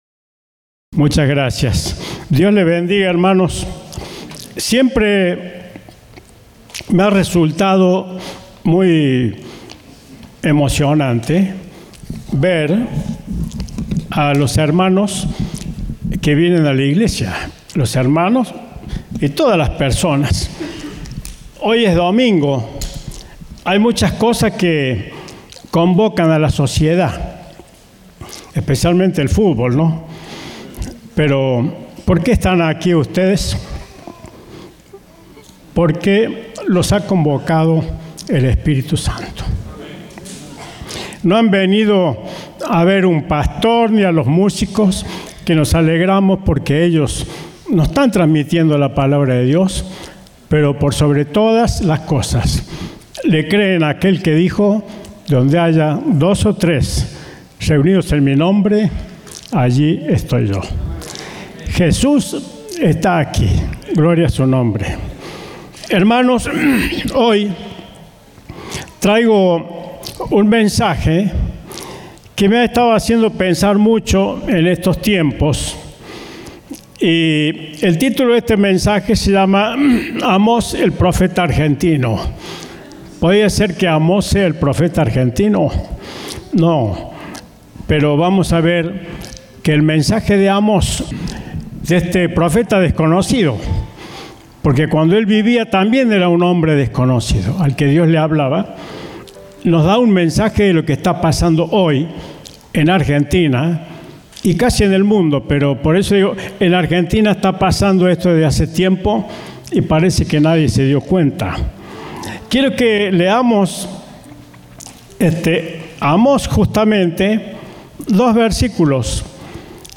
Compartimos el mensaje del Domingo 14 de Setiembre de 2025